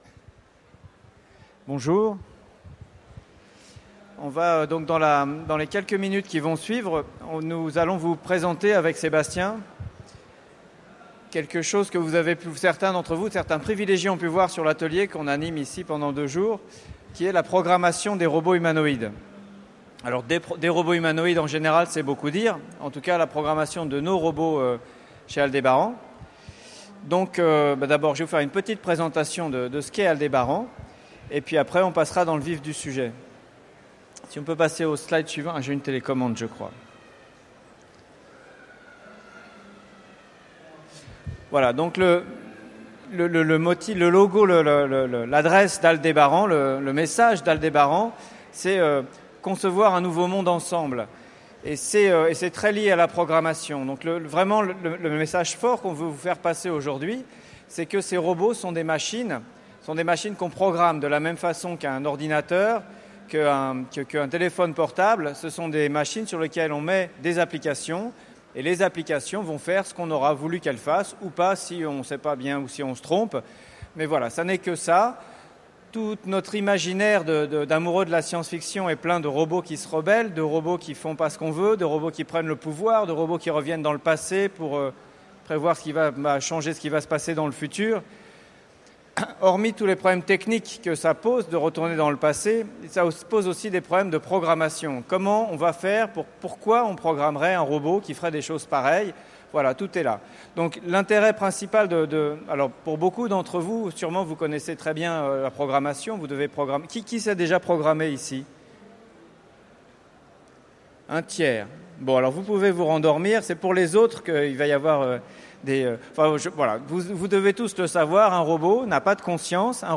Utopiales 2015 : La programmation d'un robot Nao, démonstration
Mots-clés Robot Conférence Partager cet article